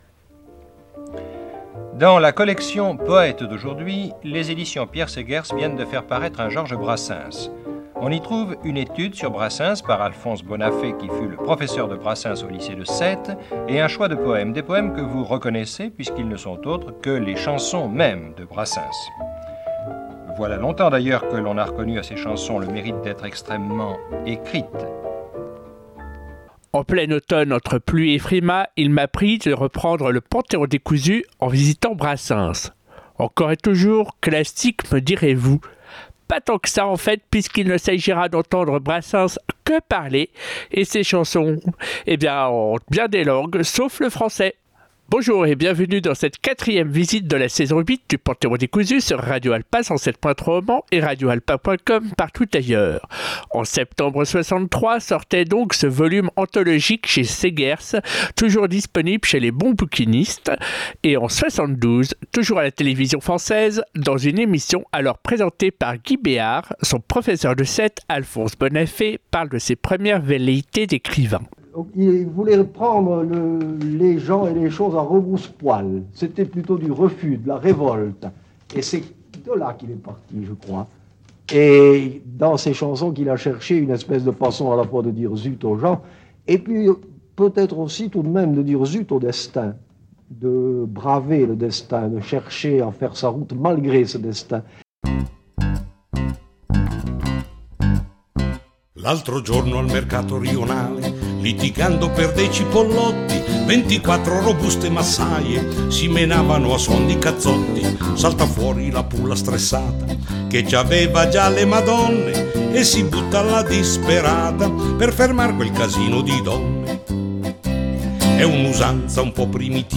Créole, espagnol, lombard, japonais… autant de couleurs et de rythmes qui nous font vivre autrement un répertoire si proche et… si français ?